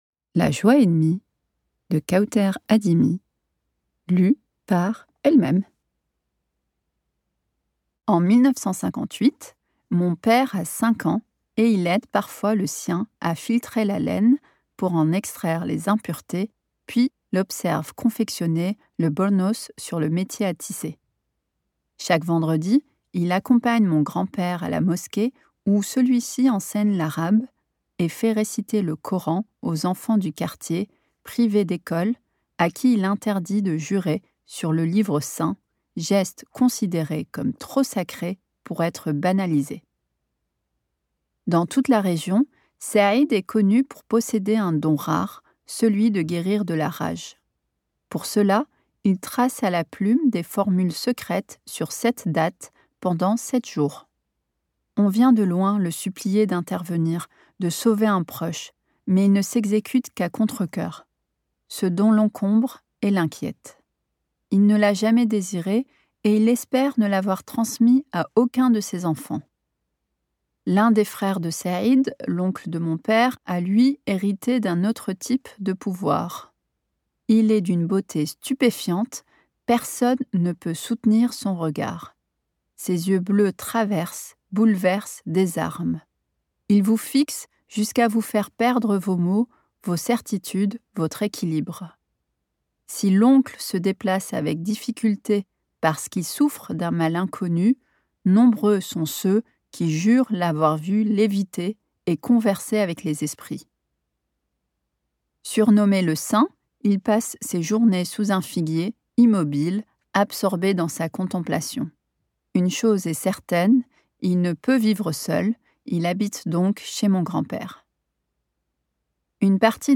je découvre un extrait - La Joie ennemie de Kaouther ADIMI
Interprétation humaine Durée : 04H13 × Guide des formats Les livres numériques peuvent être téléchargés depuis l'ebookstore Numilog ou directement depuis une tablette ou smartphone.